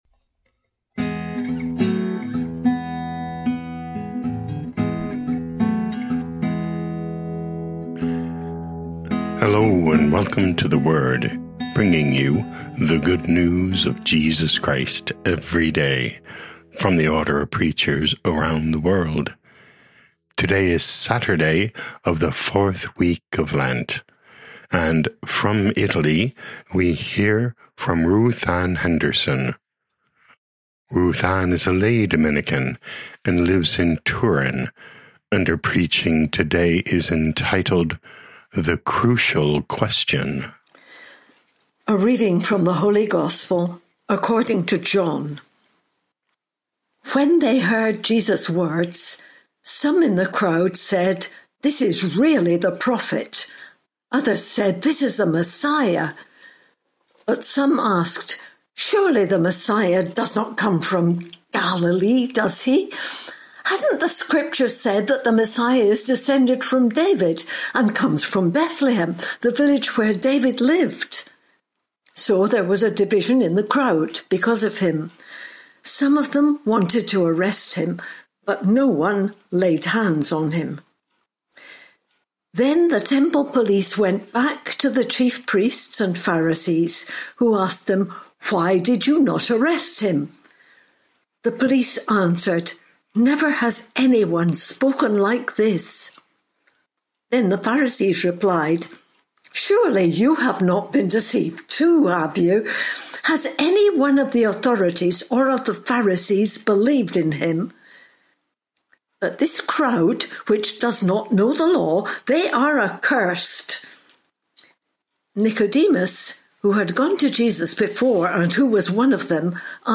21 Mar 2026 The Crucial Question Podcast: Play in new window | Download For 21 March 2026, Saturday of the 4th week of Lent, based on John 7:40-52, sent in from Turin, Italy.
Preaching